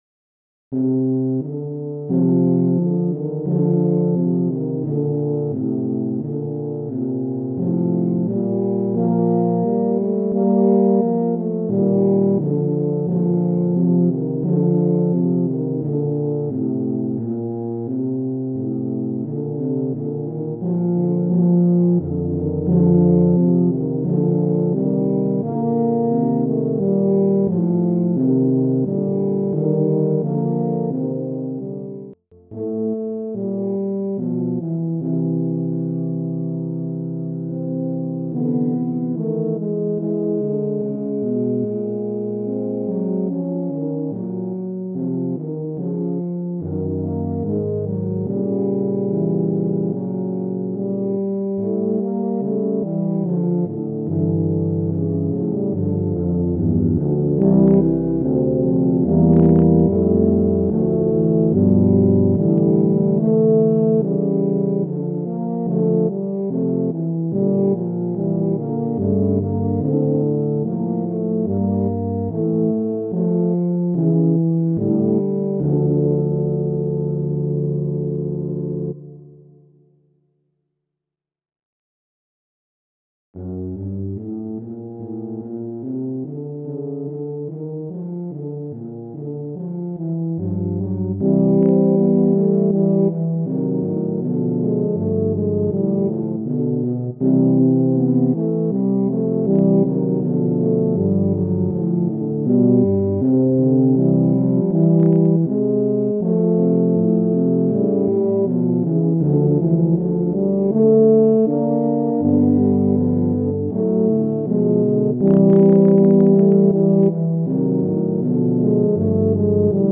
テューバ4重奏